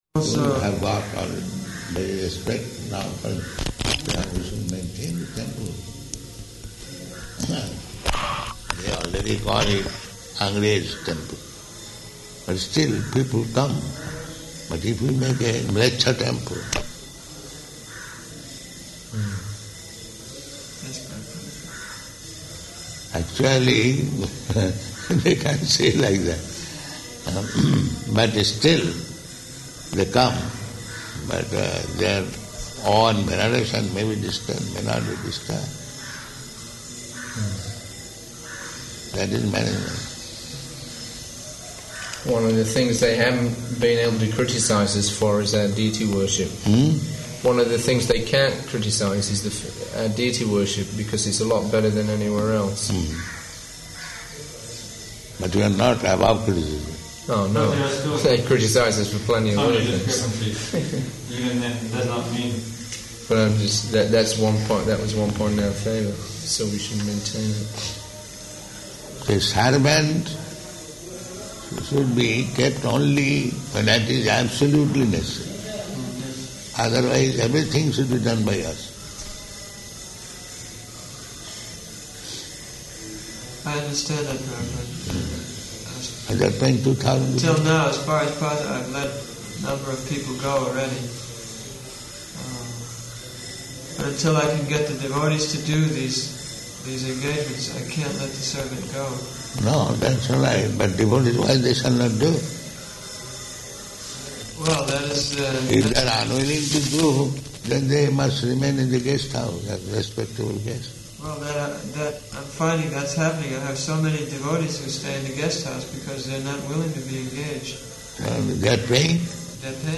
Room Conversation
Room Conversation --:-- --:-- Type: Conversation Dated: October 4th 1976 Location: Vṛndāvana Audio file: 761004R1.VRN.mp3 Prabhupāda: People have got already very respect now for the...